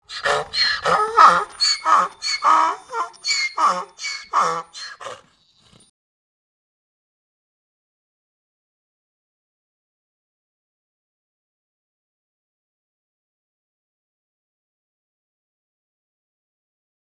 دانلود آهنگ خر (الاغ) برای کودکان از افکت صوتی انسان و موجودات زنده
دانلود صدای خر (الاغ) برای کودکان از ساعد نیوز با لینک مستقیم و کیفیت بالا
جلوه های صوتی